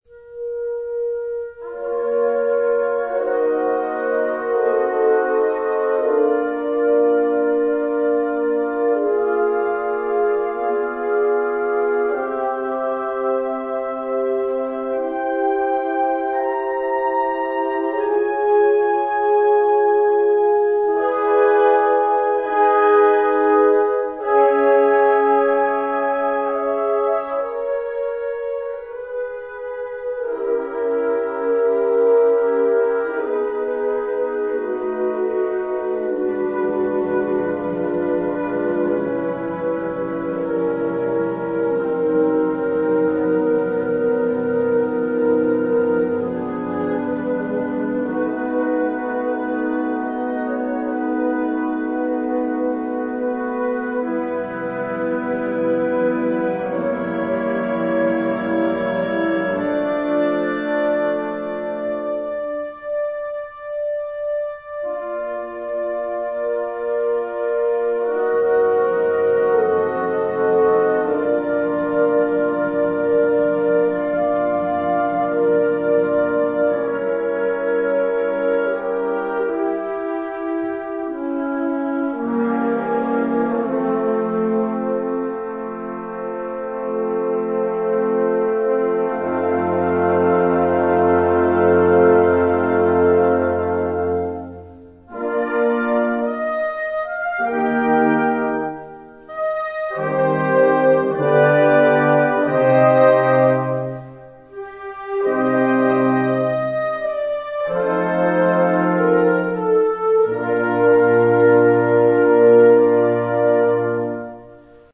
Kategorie Blasorchester/HaFaBra
Besetzung Ha (Blasorchester)